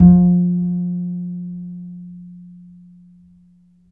DBL BASS GN3.wav